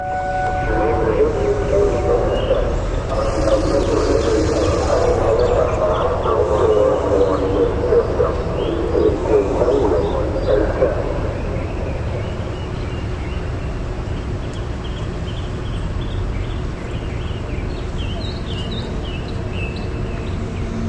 户外警告
描述：来自砖头建筑间的户外警报系统测试的部分内容。民间密集的警报器、公共广播信息、建筑设备和春天的鸣鸟。